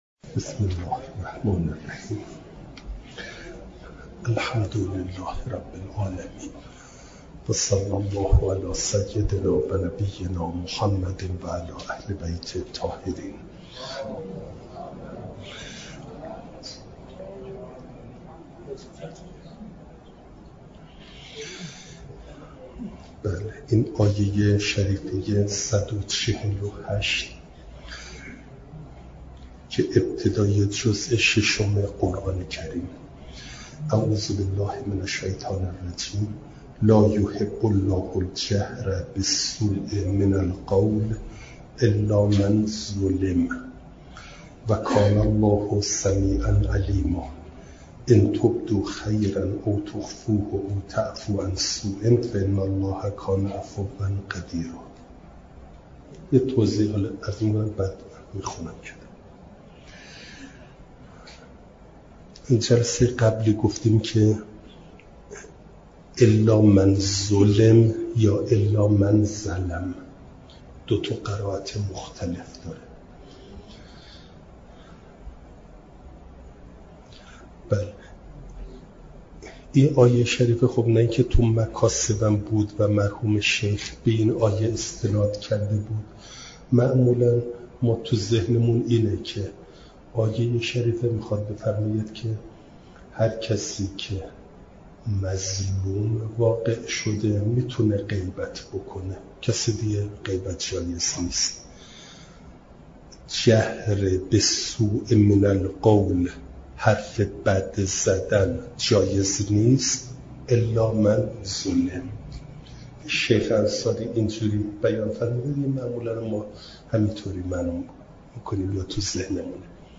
جلسه چهارصد و دوم درس تفسیر مجمع البیان